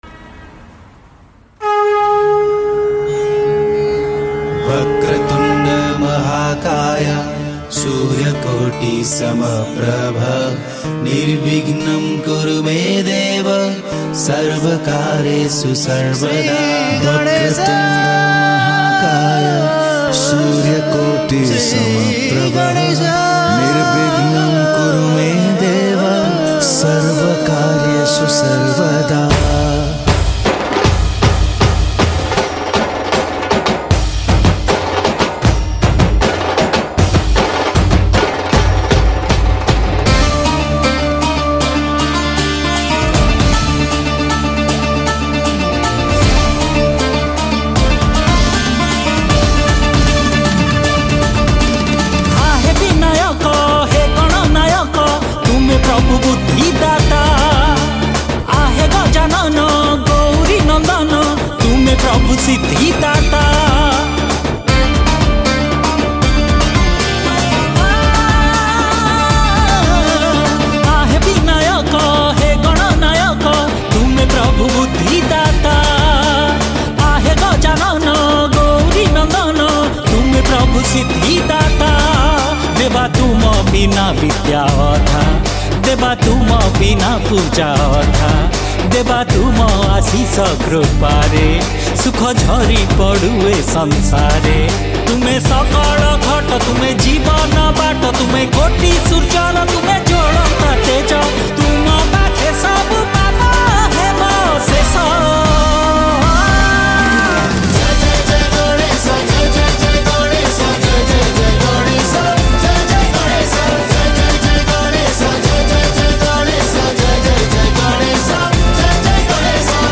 ♪ Keyboard Programming
♪ Drums